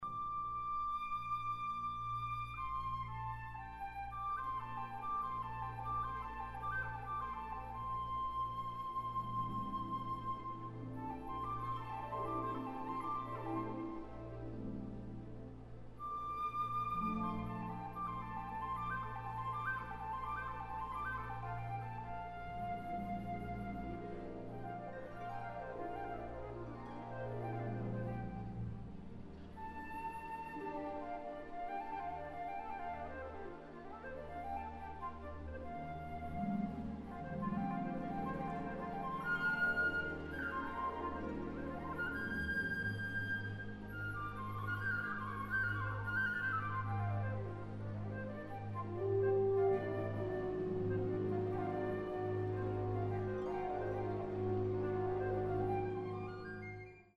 ニューフィルの定期演奏会は、無事に終わりました。
でも、実際はそこで少しレベルを下げたので、今回はとてもきれいに録音出来ました。
私がソロを吹く「くじゃく」では、もろにハープとの絡みがあるのですが、何の不安もなく吹くことが出来ましたからね。
まあ、指はちょっともつれていますが、音はしっかりしていたような。